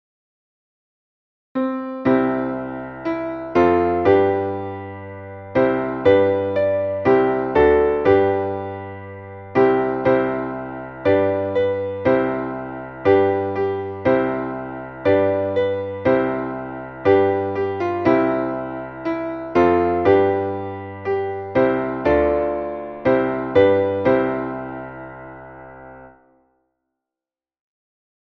Traditionelles Advents-/ Hirten-/ Weihnachtslied